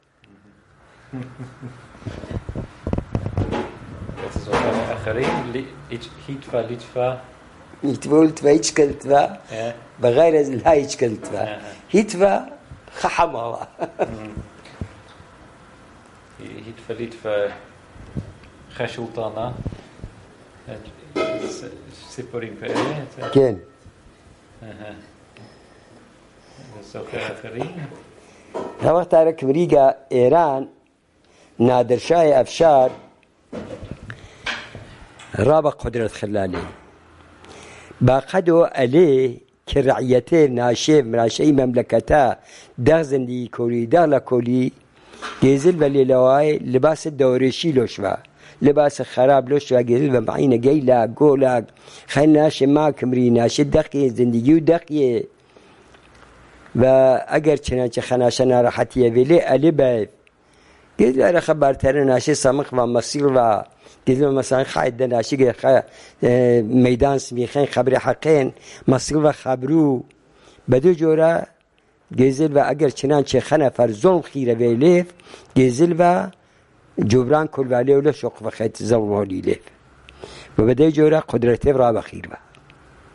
Sanandaj, Jewish: Nadir Shah